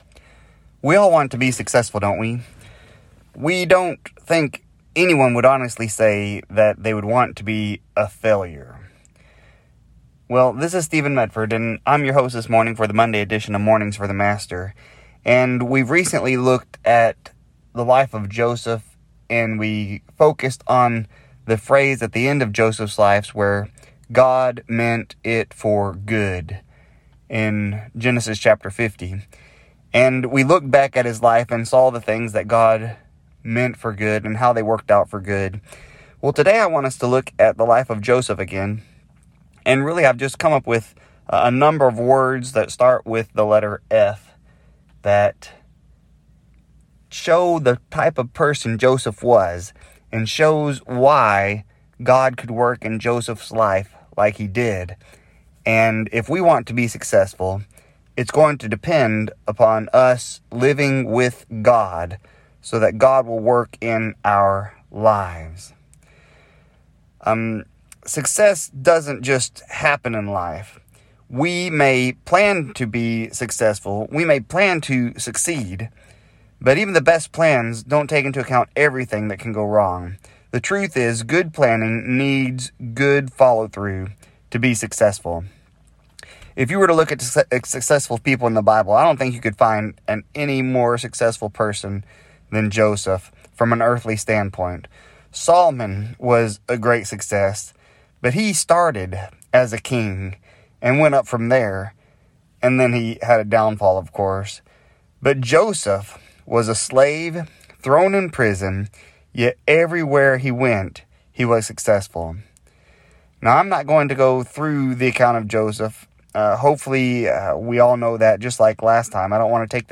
Program Info: Live program from the TGRN studio in Mount Vernon, TXClick here for current program schedule.